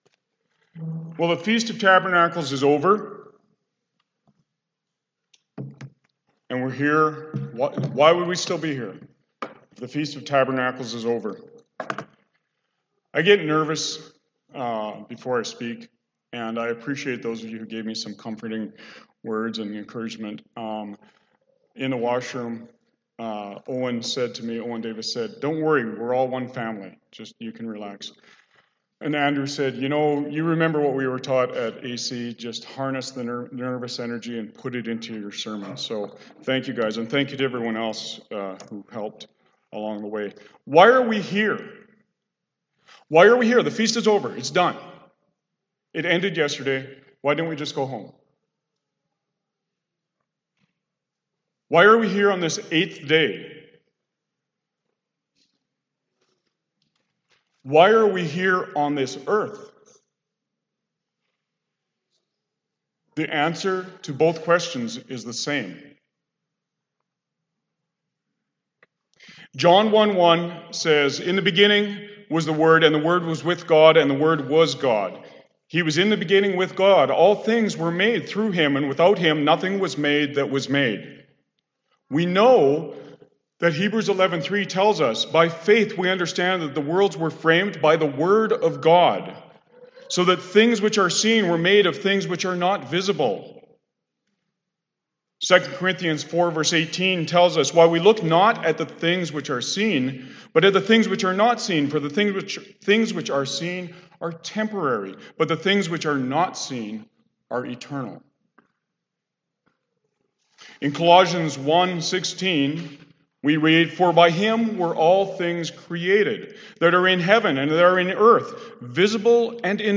This sermon was given at the Cochrane, Alberta 2020 Feast site.